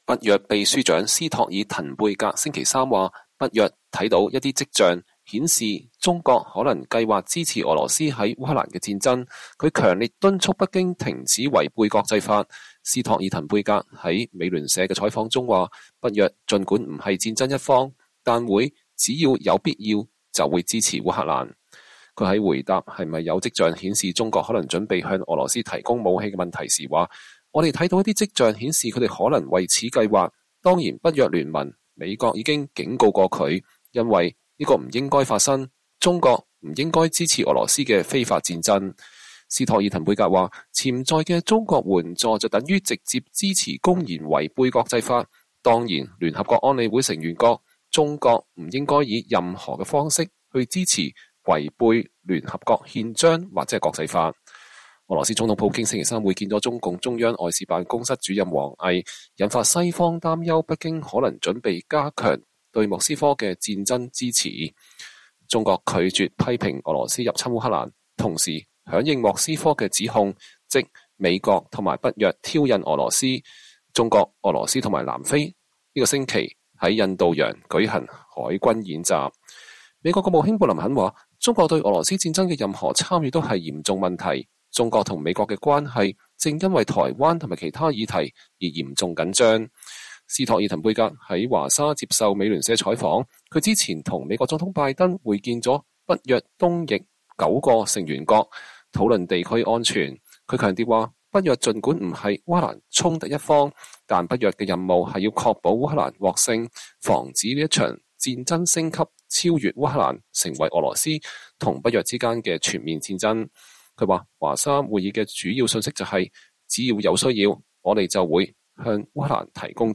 北約秘書長斯托爾滕貝格接受美聯社採訪。（2023年2月22日）